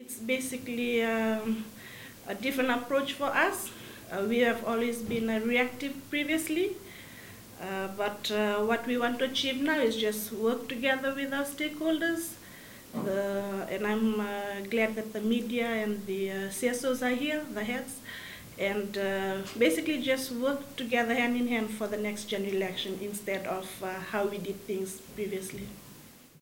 Acting Supervisor of Elections, Ana Mataiciwa.